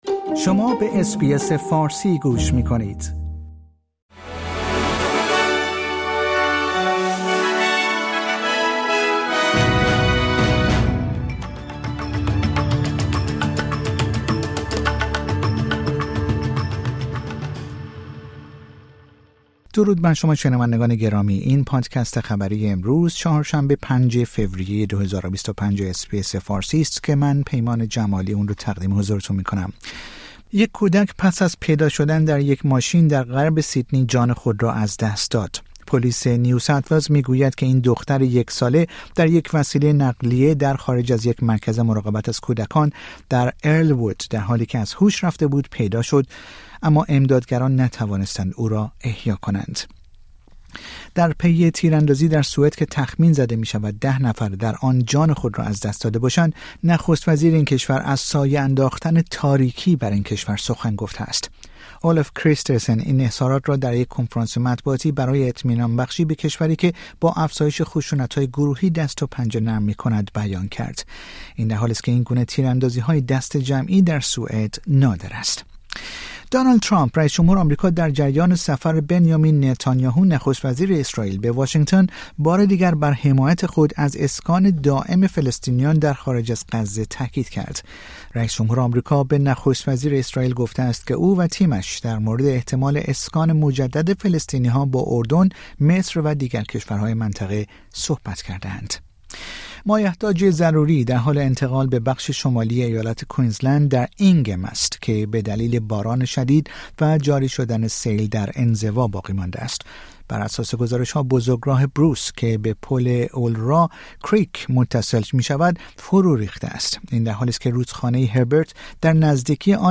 در این پادکست خبری مهمترین اخبار استرالیا در روز چهارشنبه ۵ فوریه ۲۰۲۵ ارائه شده است.